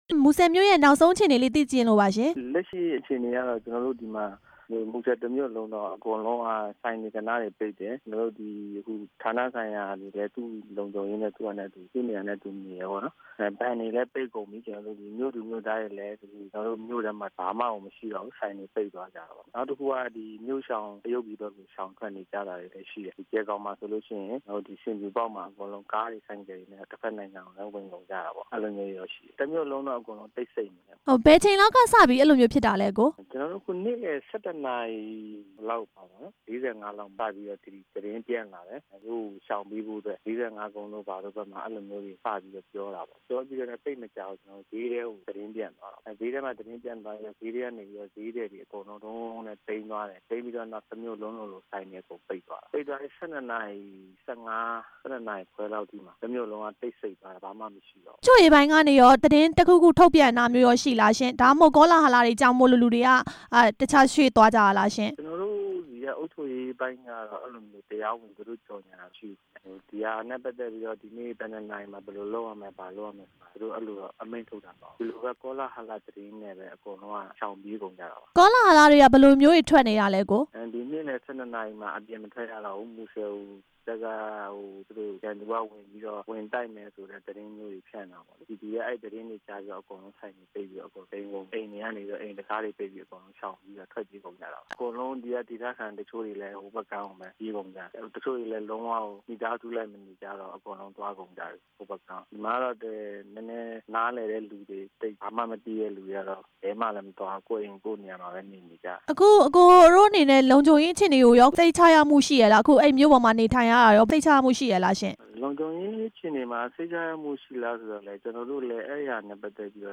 မူဆယ်မြို့ အခြေအနေ မေးမြန်းချက်